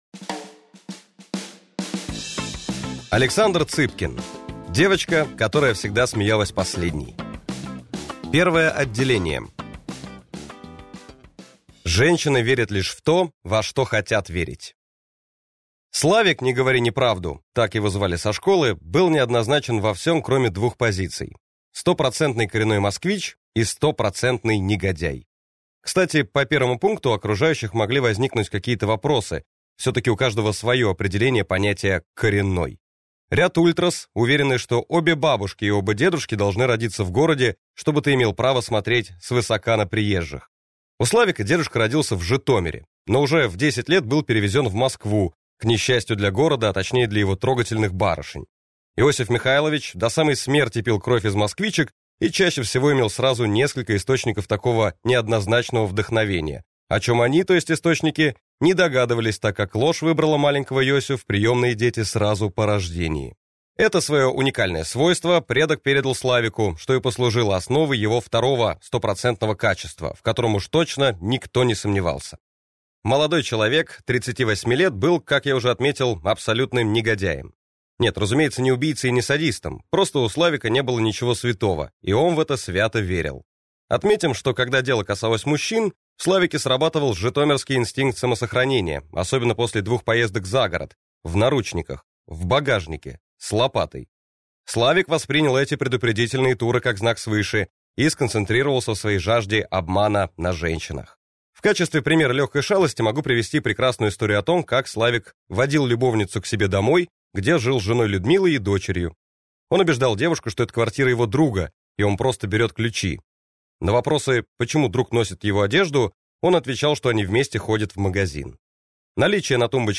Аудиокнига Девочка, которая всегда смеялась последней - купить, скачать и слушать онлайн | КнигоПоиск